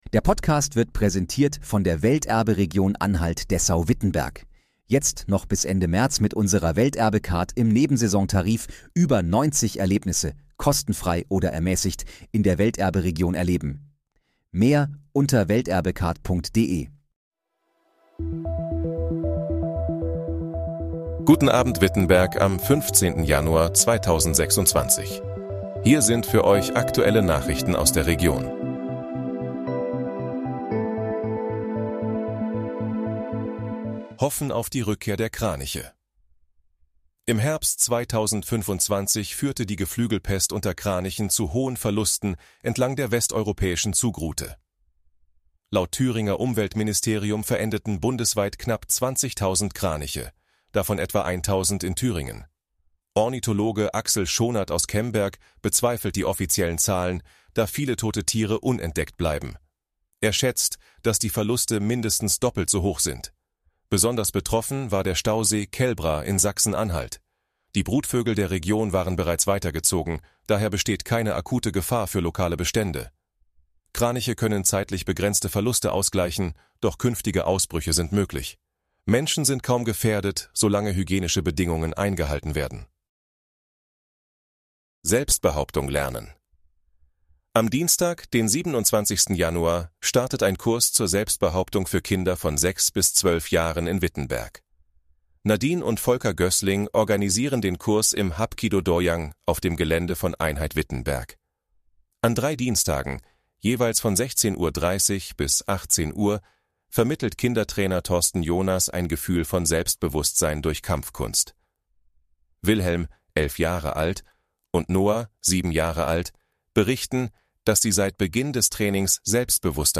Guten Abend, Wittenberg: Aktuelle Nachrichten vom 15.01.2026, erstellt mit KI-Unterstützung
Nachrichten